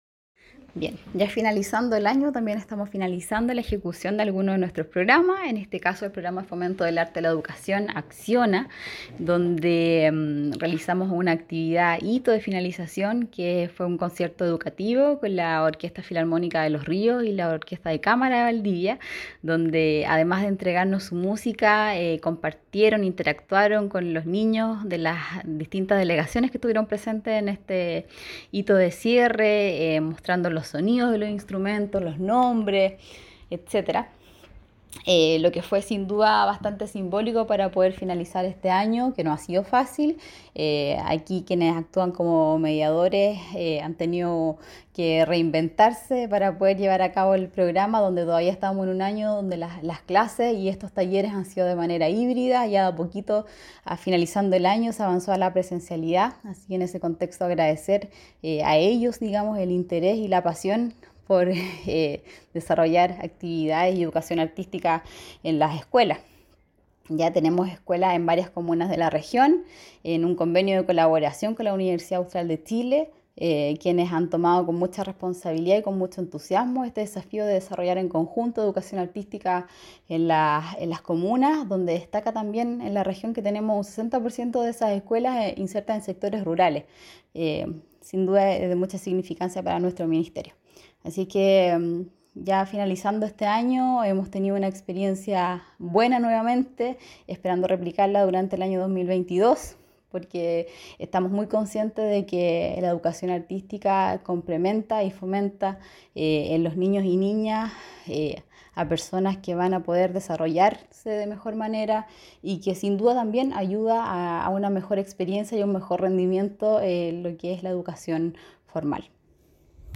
Cuña-Seremi-de-las-Culturas-Gabriela-Avendaño-por-programa-Acciona.mp3